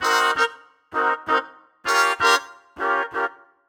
Index of /musicradar/gangster-sting-samples/130bpm Loops
GS_MuteHorn_130-CG.wav